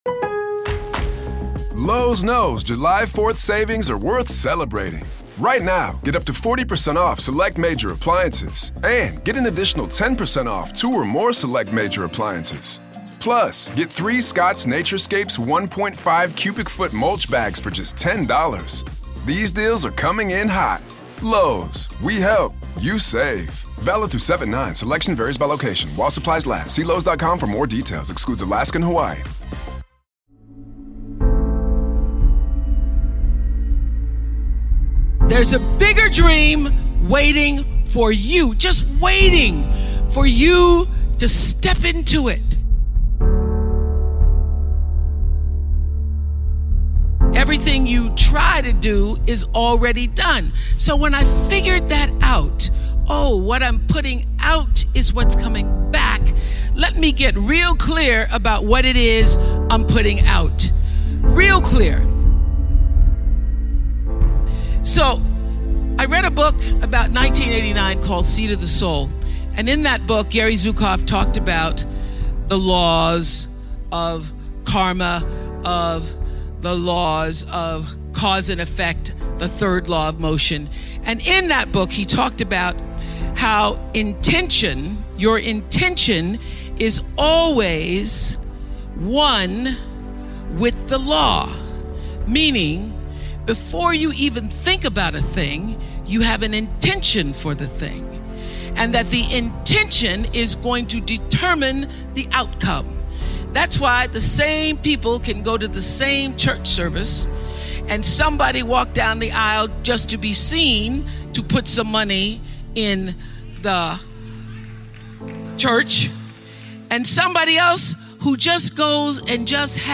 Absolutely fascinating motivational speech by one of the most influencing woman, Oprah Winfrey.
Speaker: Oprah Winfrey